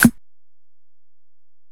Perc.wav